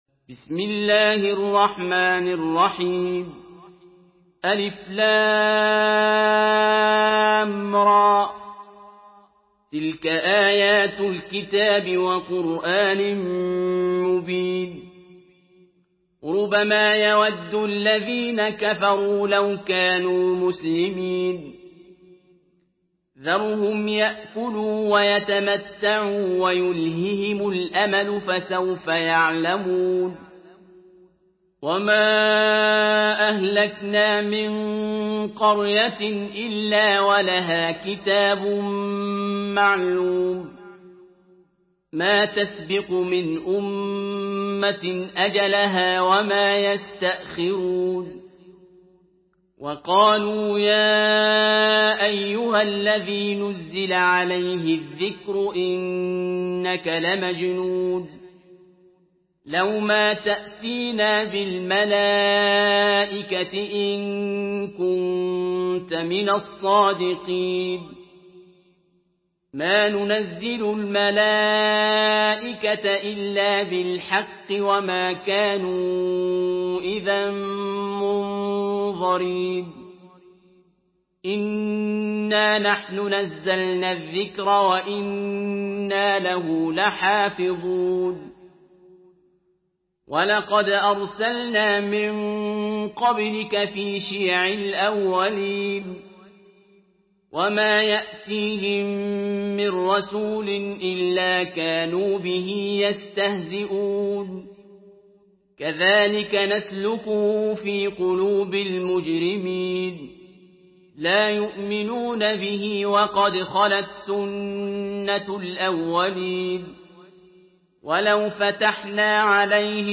Surah Al-Hijr MP3 in the Voice of Abdul Basit Abd Alsamad in Hafs Narration
Listen and download the full recitation in MP3 format via direct and fast links in multiple qualities to your mobile phone.
Murattal Hafs An Asim